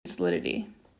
A segment of the "Spoken Text" audio file, specifically the word 'solidity'. No further effects were added.